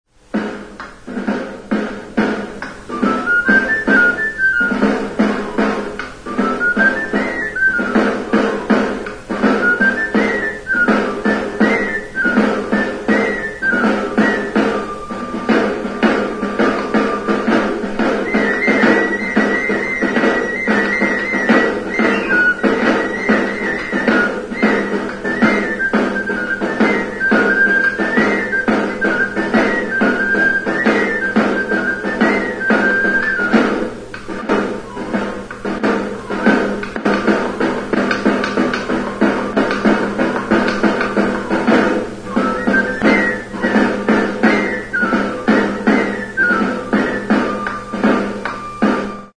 FLAUTA; Flauta de tamborilero | Soinuenea Herri Musikaren Txokoa
HM 4. jardunaldiak.
Description: Hiru zuloko flauta zuzena da.